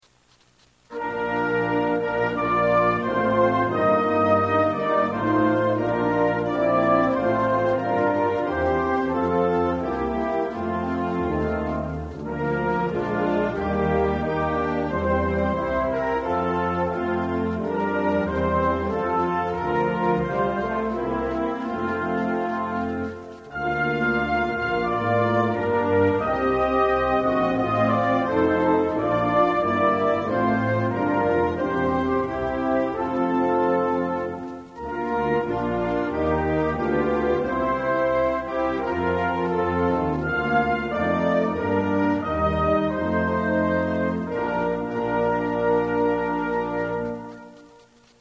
National Anthems